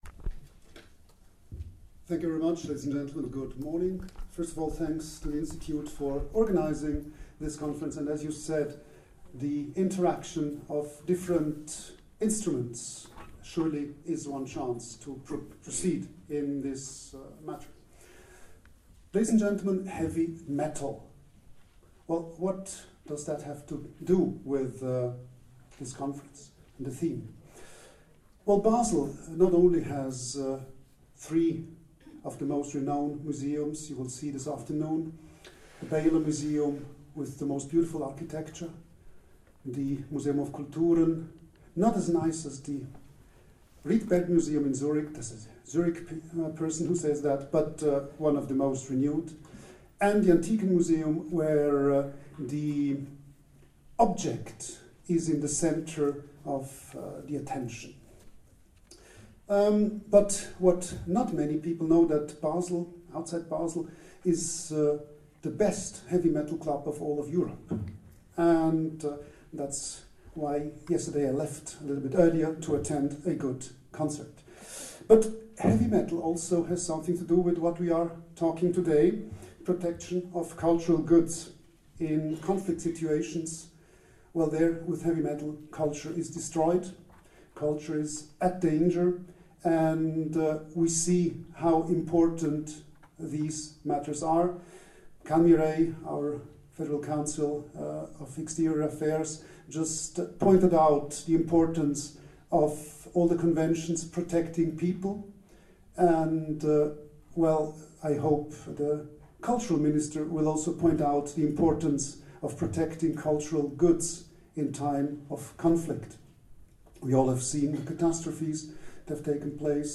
International Conference - 29 to 30 September 2009, Basel
Keynote address 30 Sept 2009